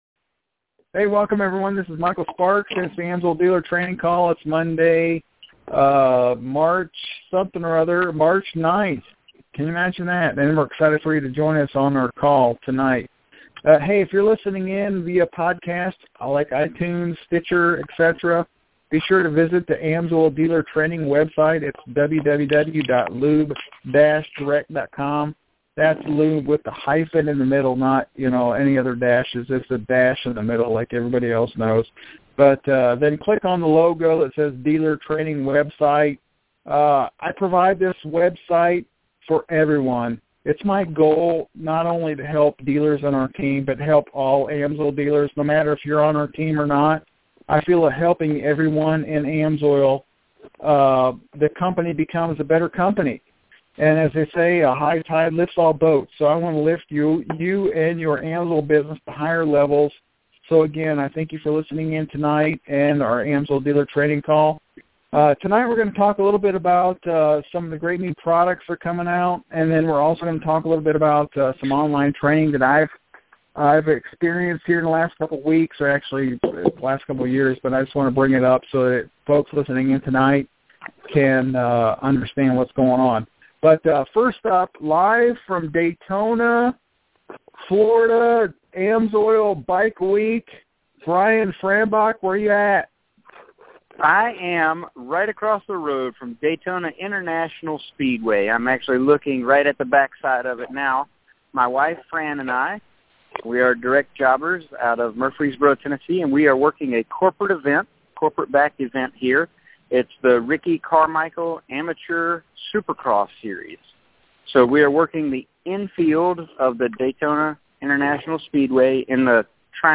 Sparks Team AMSOIL Dealer Training Call | March 9th, 2015